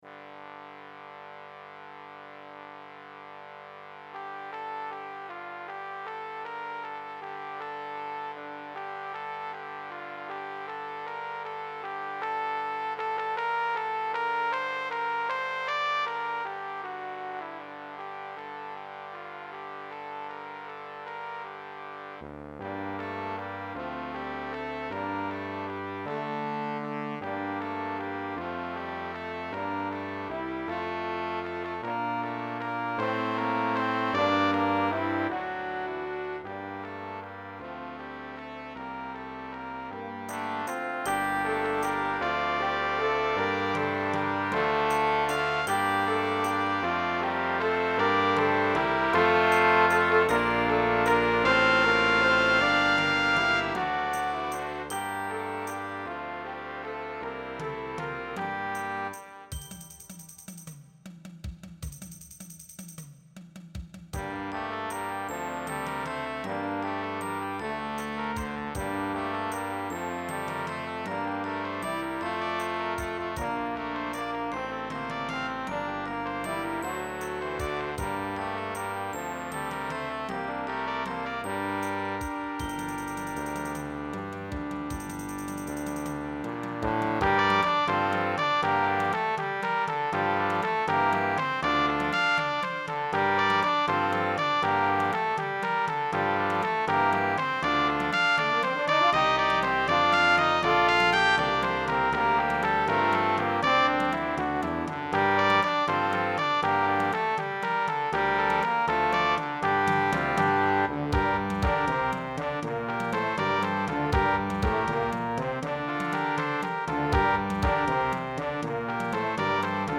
albeit in a ‘faux Celtic’ context.